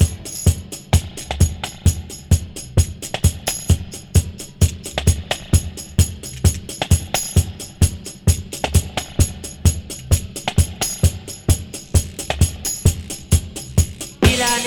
• 131 Bpm Classic Drum Loop Sample F Key.wav
Free drum loop - kick tuned to the F note. Loudest frequency: 2533Hz
131-bpm-classic-drum-loop-sample-f-key-S6S.wav